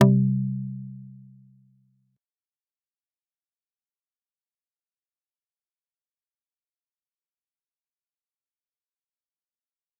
G_Kalimba-C3-pp.wav